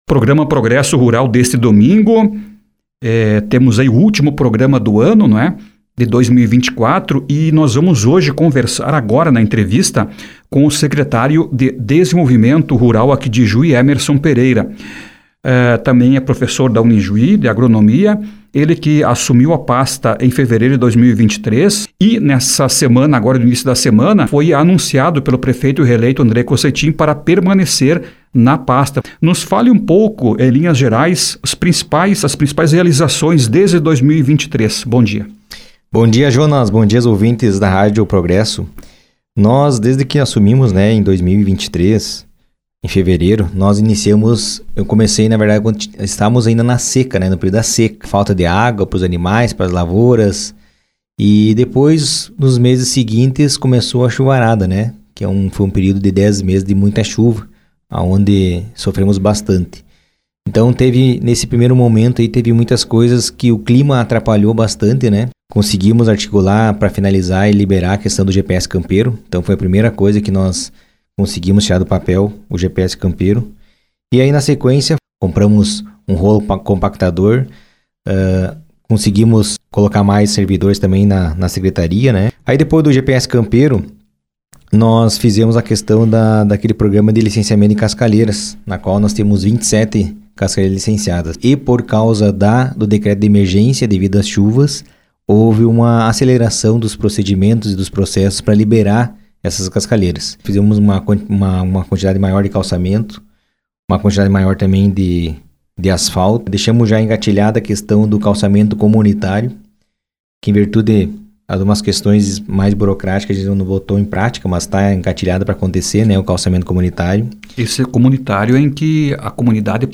Ontem, às 6 horas e 30 minutos, Émerson Pereira concedeu entrevista no programa Progresso Rural da RPI, a fim de explanar mais sobre as inciativas já implementadas e projetos para a administração vindoura.
Abaixo, confira a entrevista com Emerson Pereira: